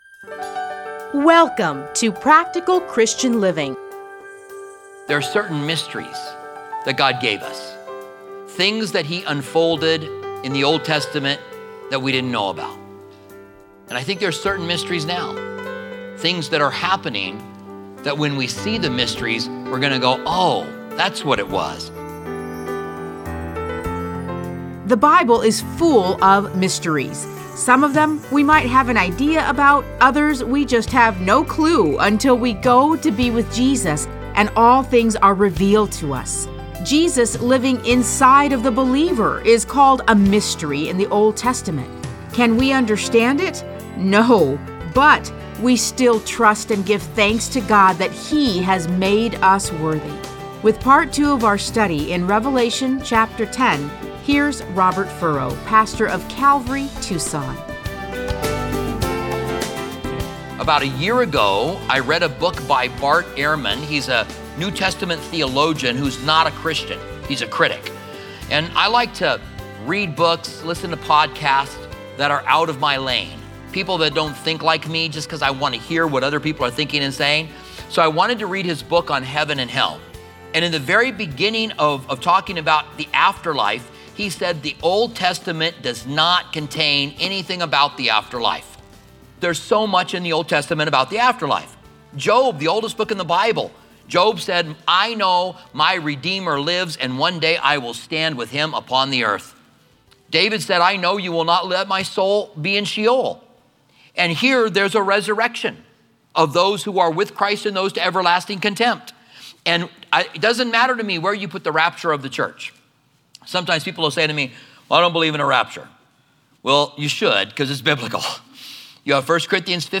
Listen to a teaching from Revelation 10:1-11.